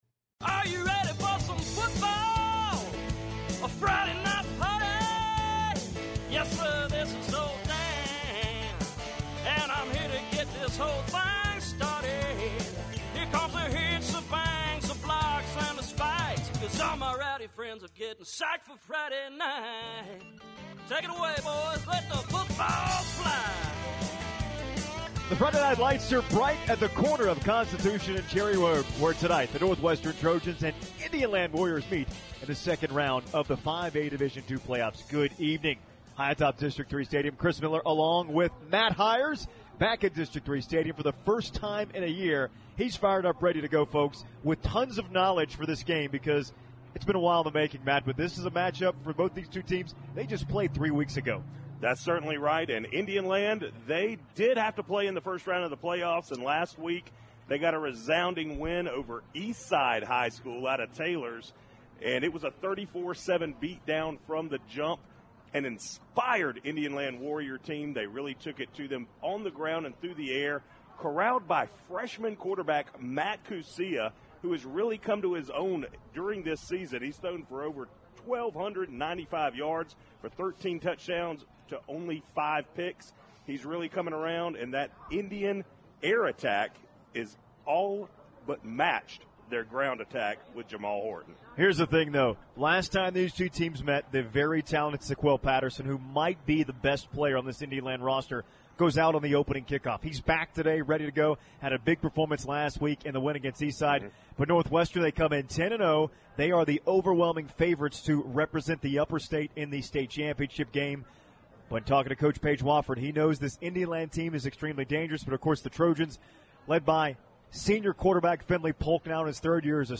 High School Sports